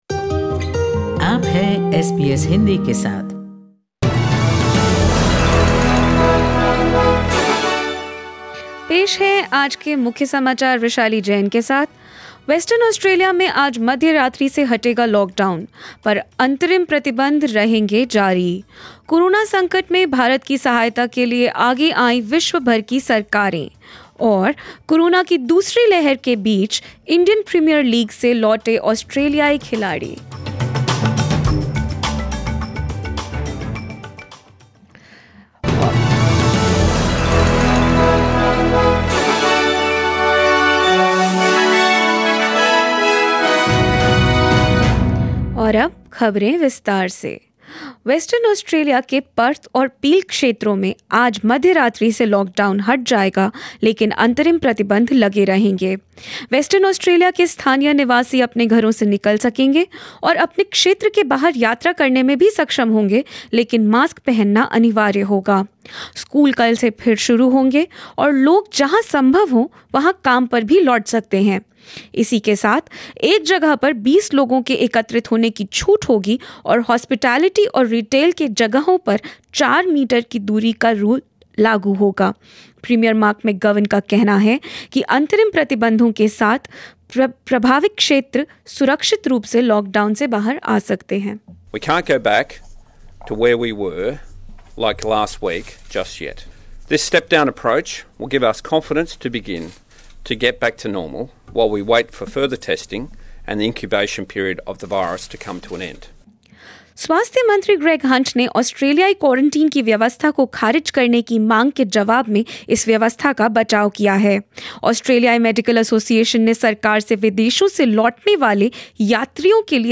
Listen to the latest news of India and Australia in Hindi. 26/04/21